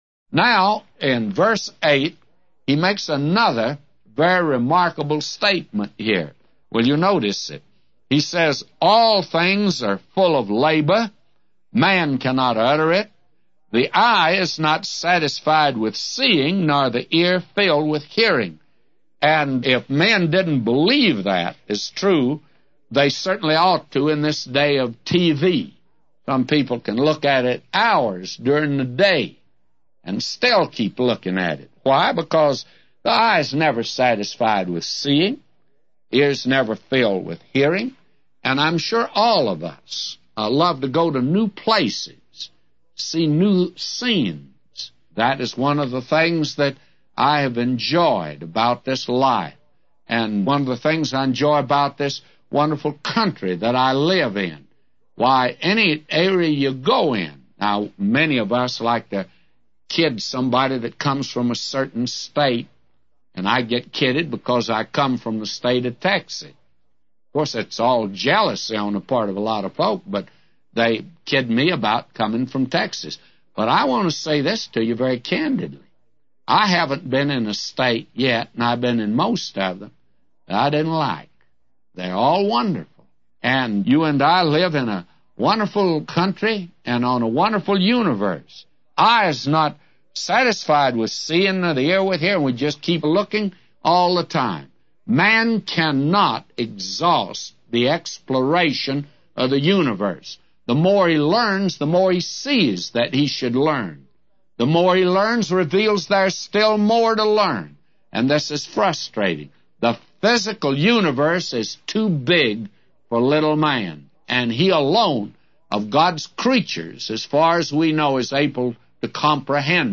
A Commentary By J Vernon MCgee For Ecclesiastes 1:8-999